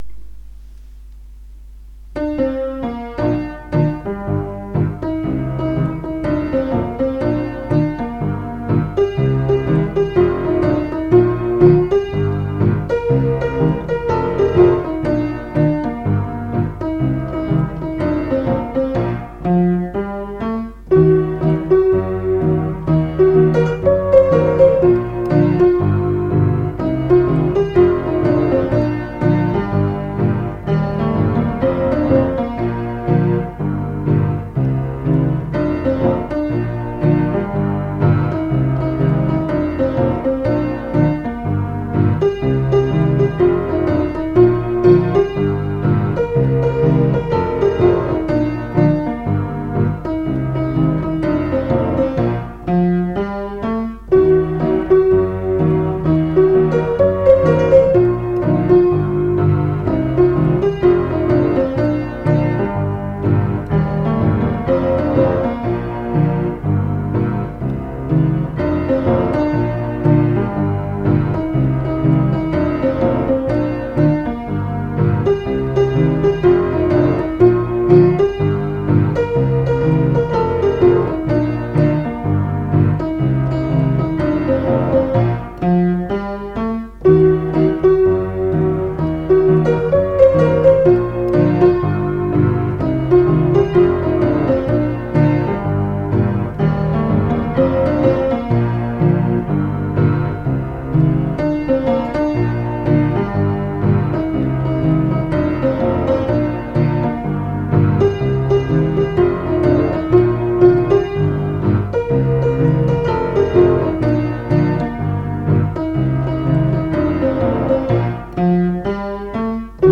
А на сайт пришлось занести мелодии в исполнении, со словами.